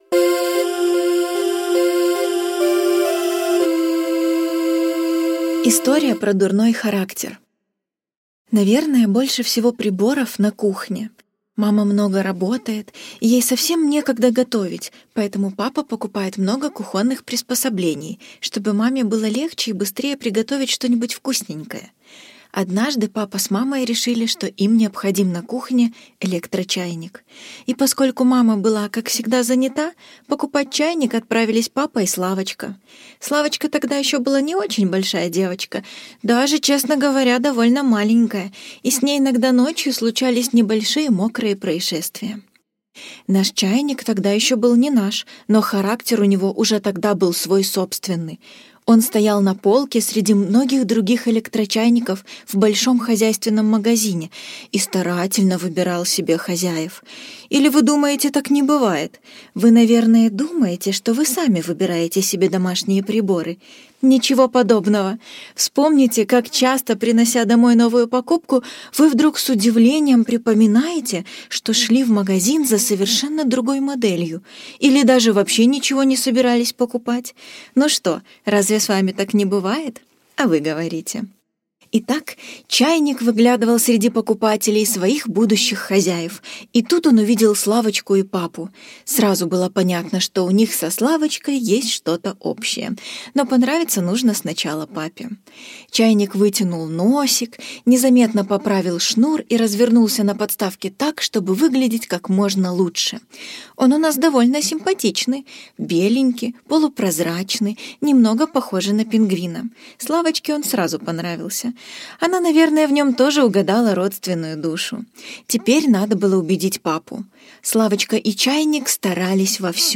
История про дурной характер - аудиосказка Кушнир - слушать онлайн | Мишкины книжки
История про дурной характер (Рассказы о домашних приборах) (аудиоверсия) – Кушнир В.